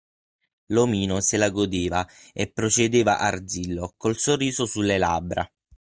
làb‧bra
/ˈlab.bra/